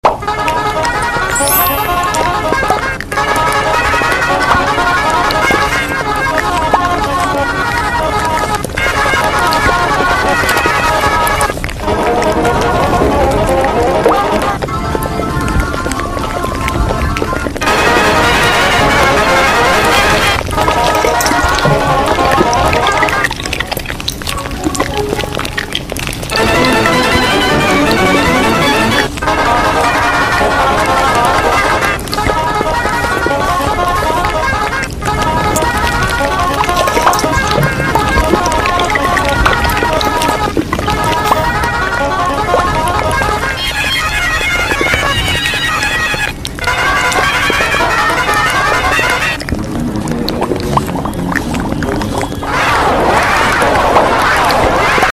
Preview 1982 cafe sound sound effects free download